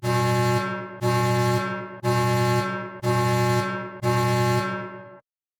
warnSound.wav